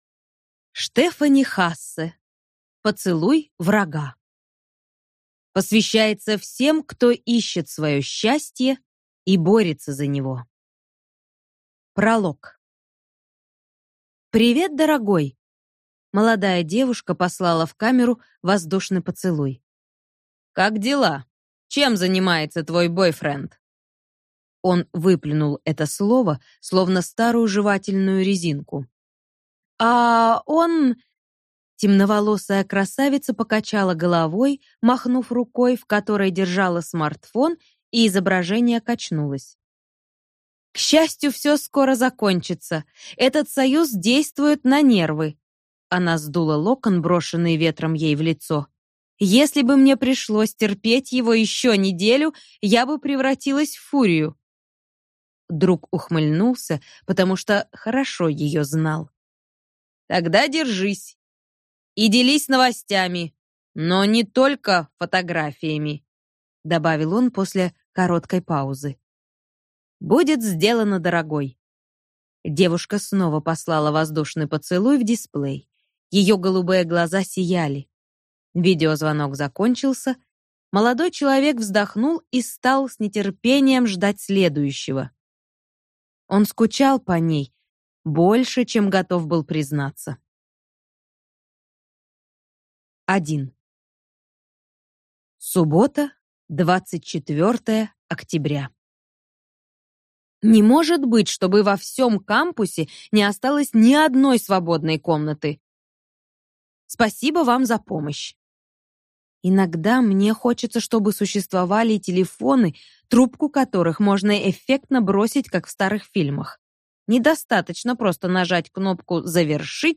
Аудиокнига Поцелуй врага | Библиотека аудиокниг